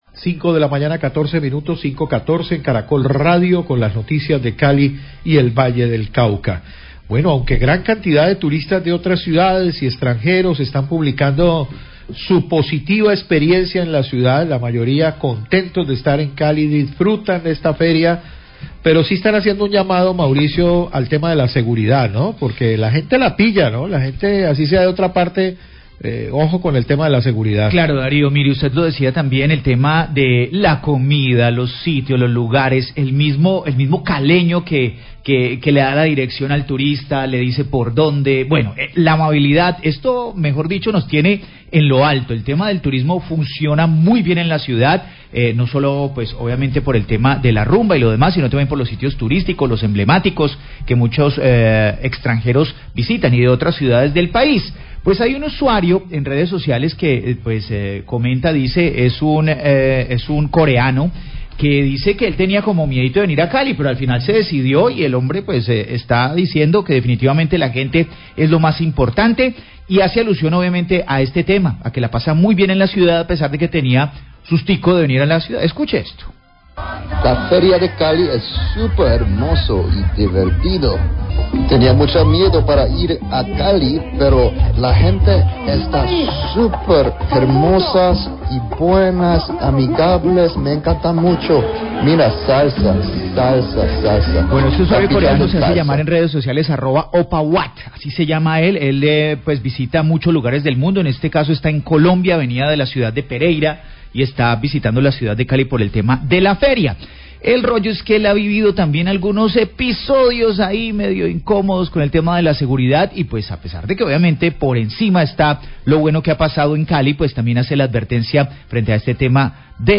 Radio
Los periodistas de noticiero comentan sobre esta situación y otra que se presentó, de un robo de un vehículo, pero que fue oportunamente atendido por las autoridades policiales y la Alcaldía de Cali.